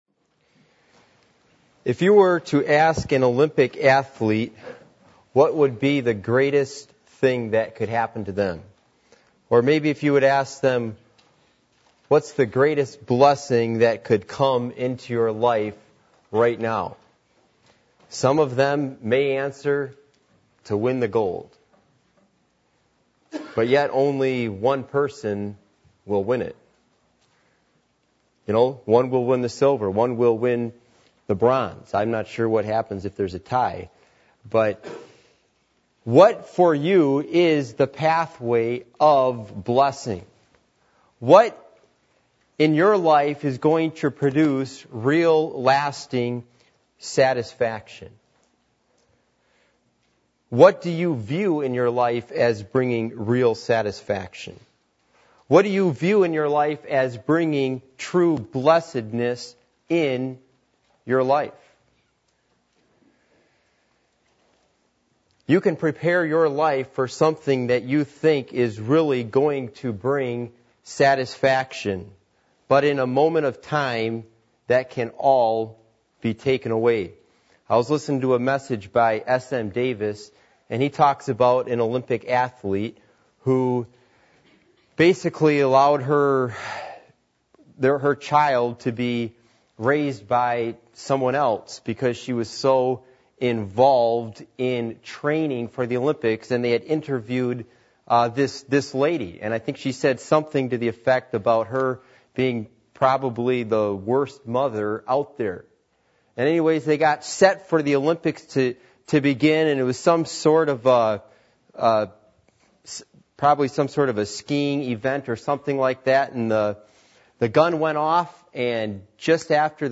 Passage: Matthew 5:3 Service Type: Midweek Meeting %todo_render% « Reverential Versus Casual Worship Communion With The Triune God